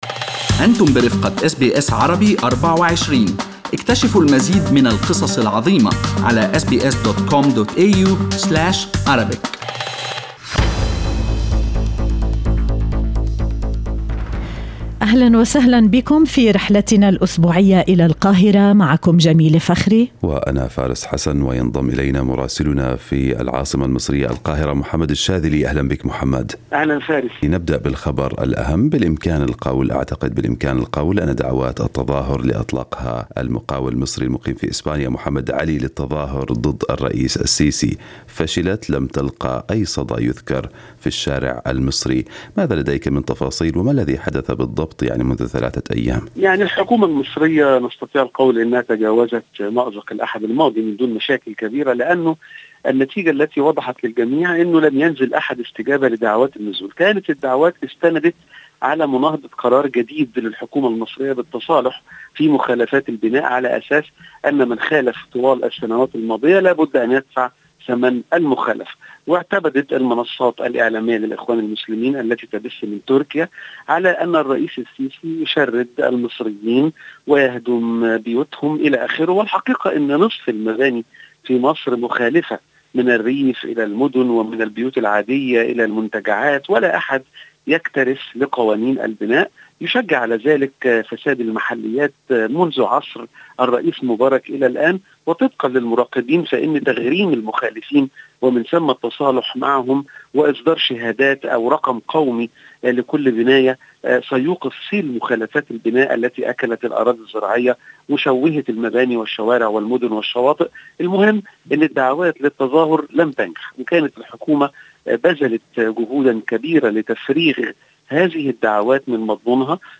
من مراسلينا: أخبار مصر في أسبوع 23/9/2020